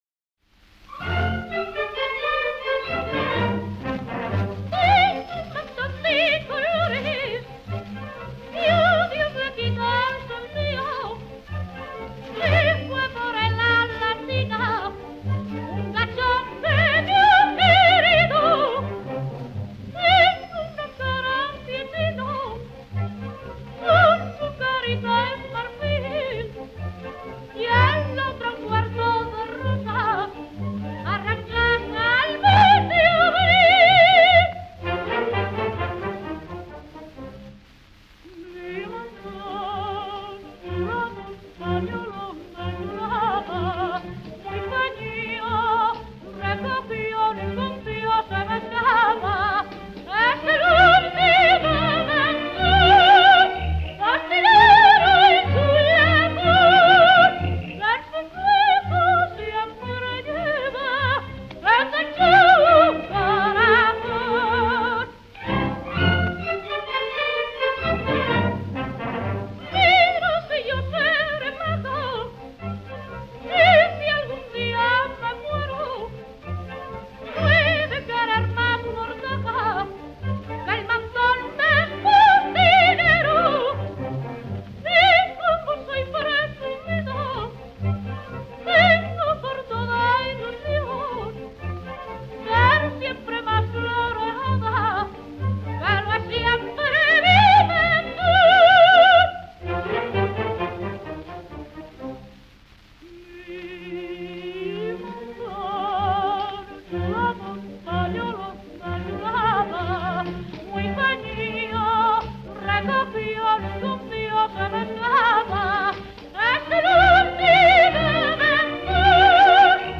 78 rpm